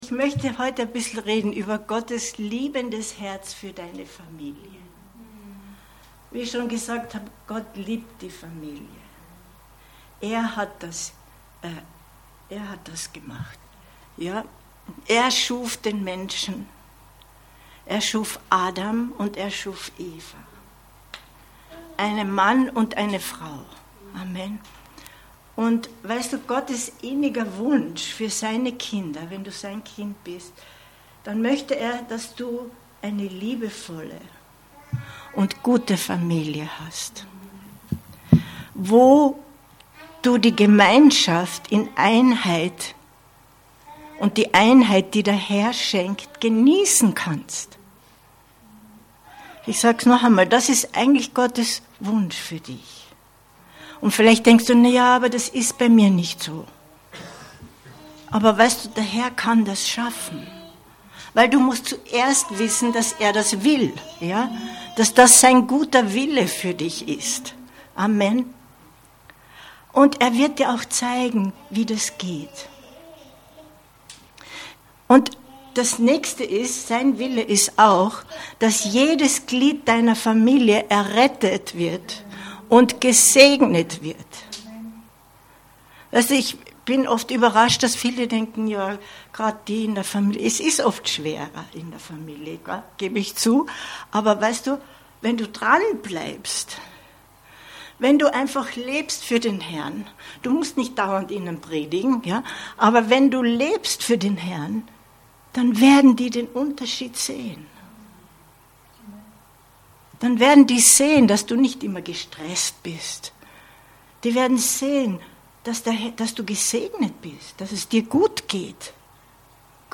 Gottes liebendes Herz für deine Familie 14.05.2023 Predigt herunterladen